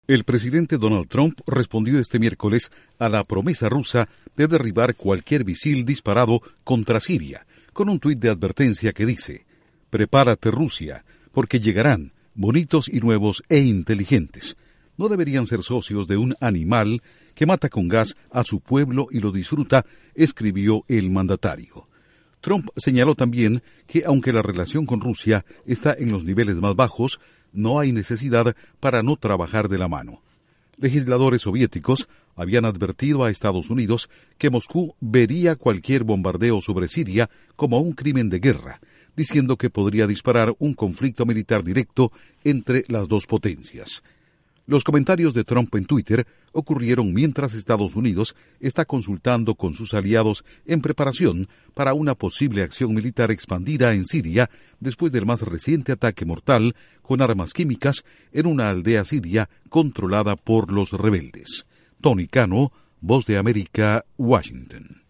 Informa desde la Voz de América en Washington